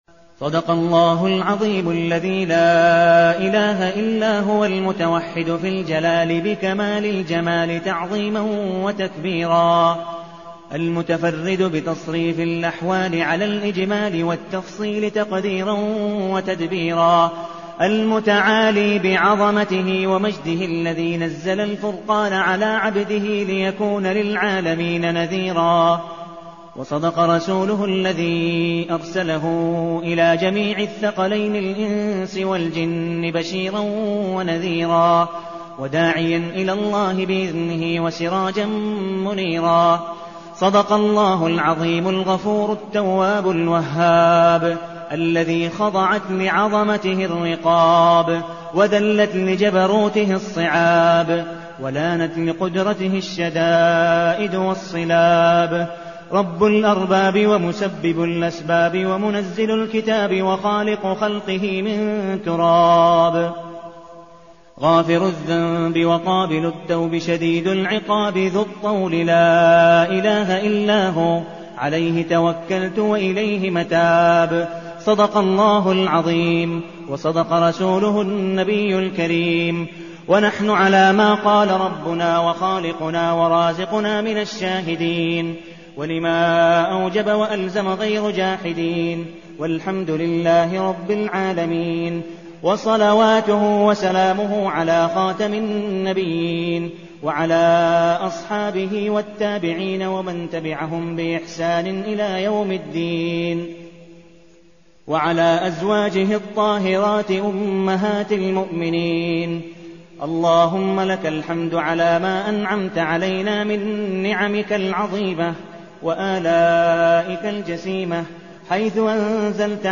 الدعاء
المكان: المسجد النبوي الشيخ